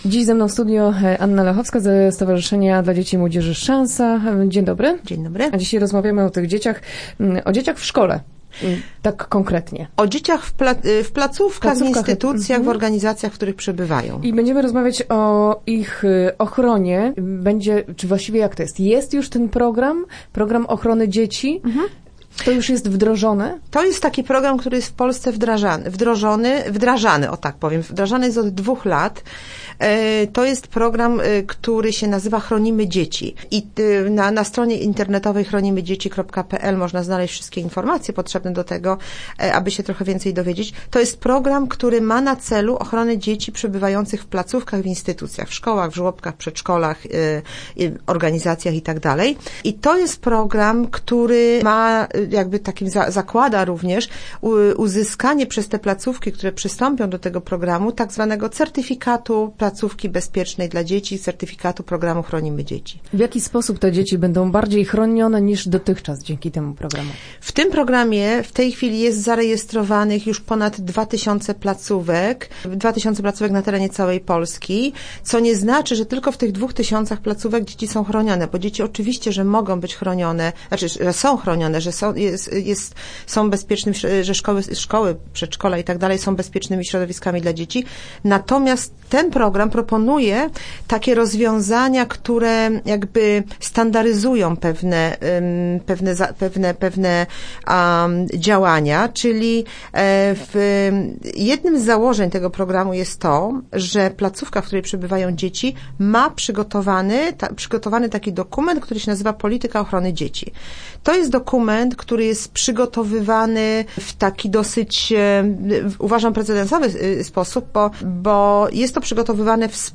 Start arrow Rozmowy Elki arrow SZANSA na ochronę dzieci